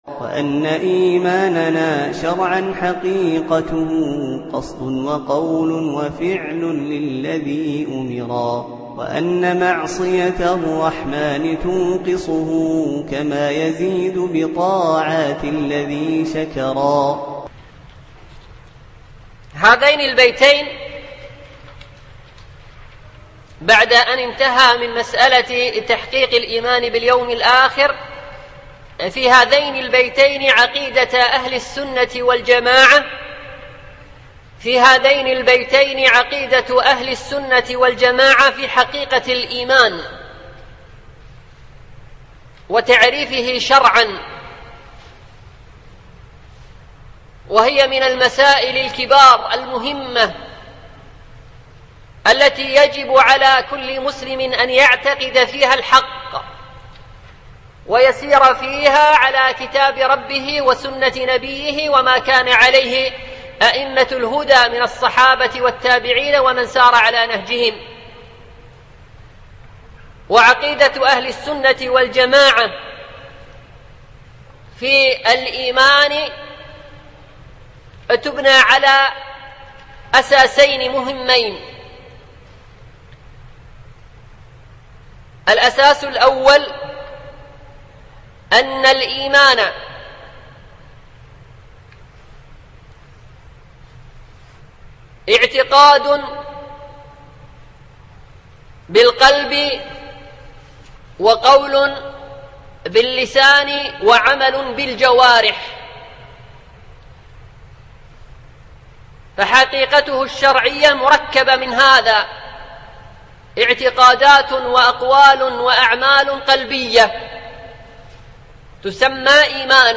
التنسيق: MP3 Mono 22kHz 32Kbps (VBR)